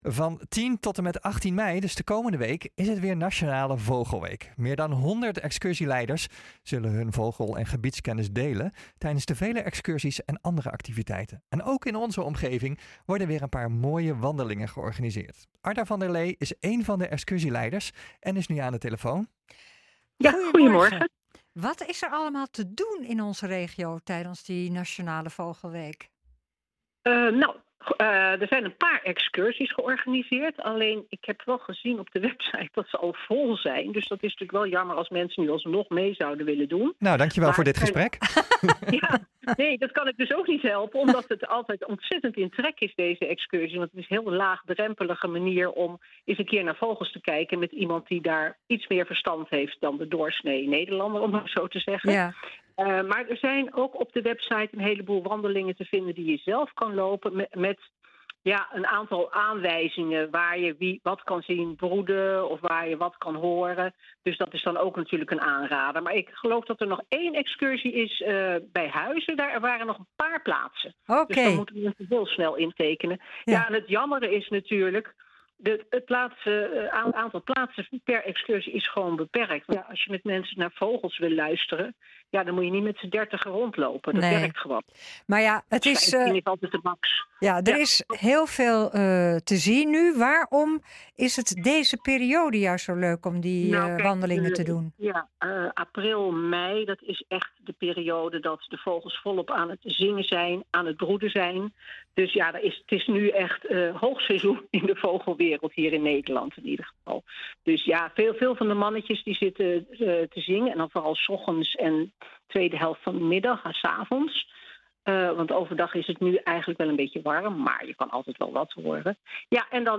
is één van de excursieleiders en nu aan de telefoon.